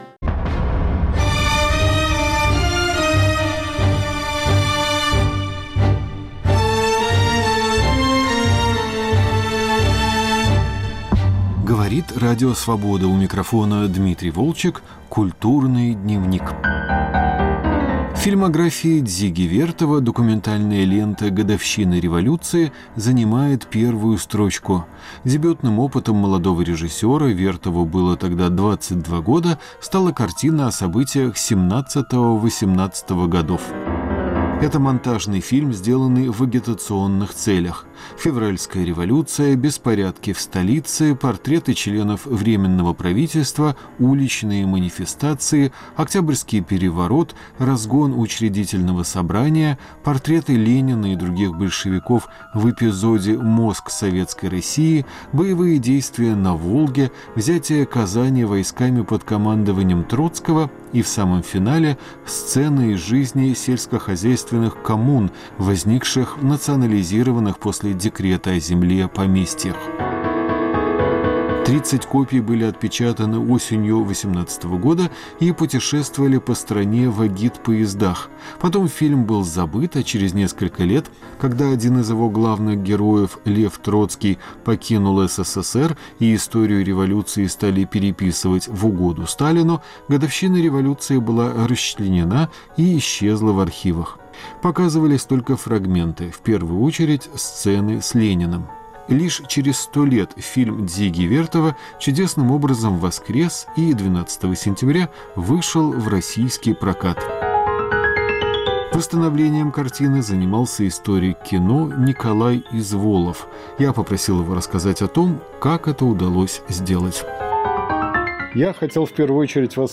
Как воскрес первый фильм Дзиги Вертова. Разговор